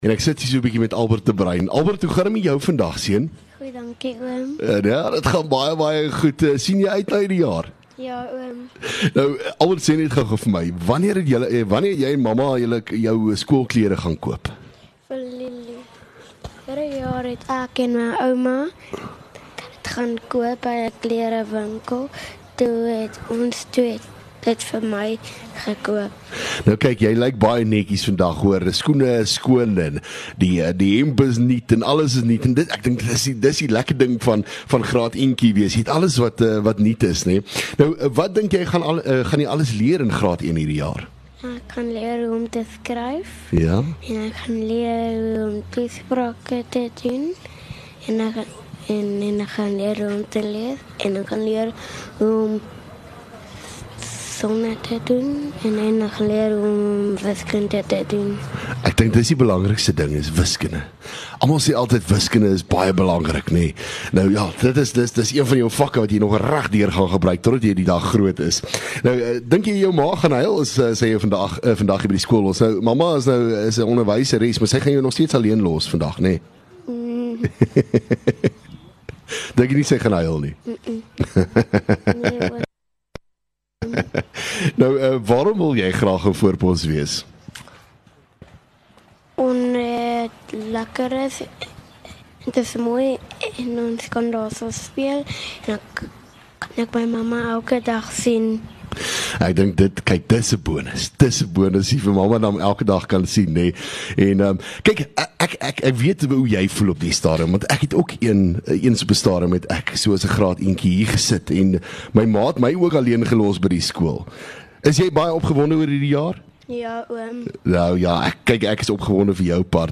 LEKKER FM | Onderhoude 14 Jan Laerskool Voorpos